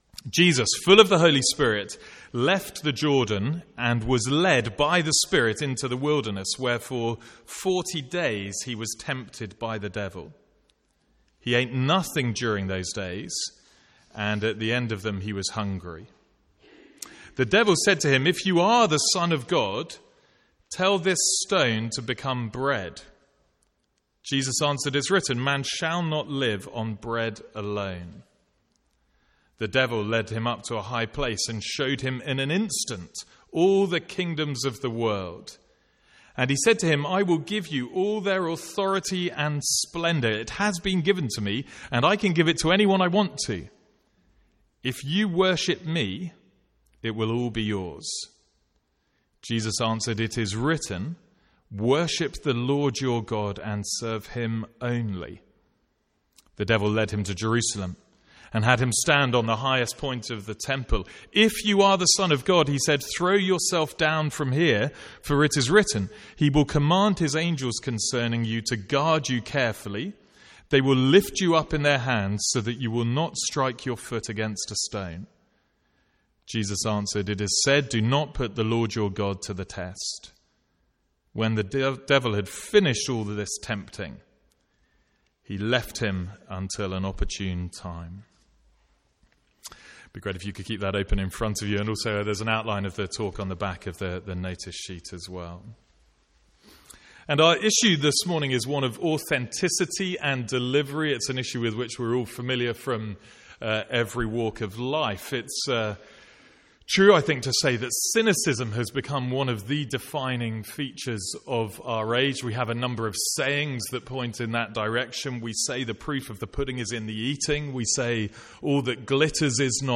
From the Sunday morning series in Luke.